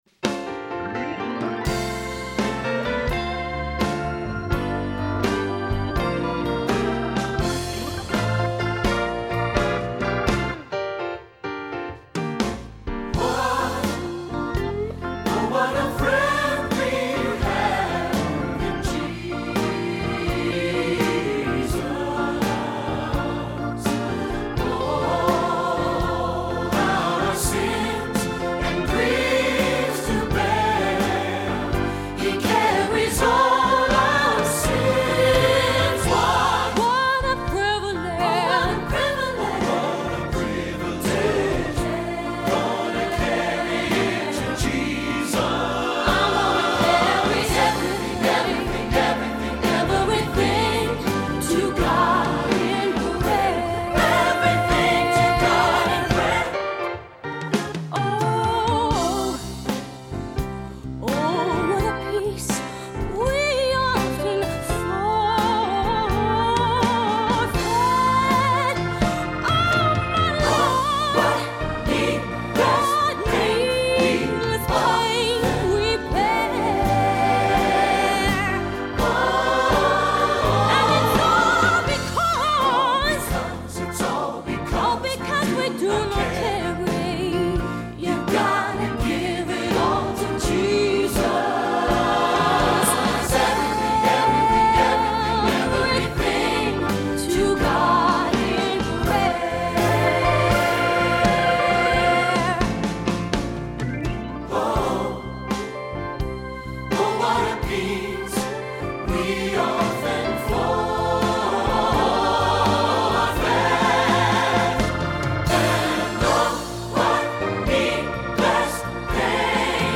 Voicing: SAB and Piano Level